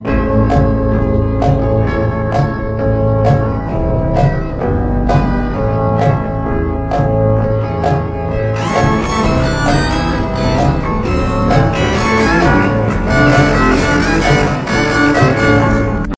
描述：Vocal line inspired by the robot Loaders in Borderlands 2. Normalized to 3dBFS, but no limiting has been applied.
标签： lethalforceauthorized voice processed morphed morph scifi robotvoice Borderlands2 robotic Loader gamevocals robot Borderlands2 gamevoice
声道立体声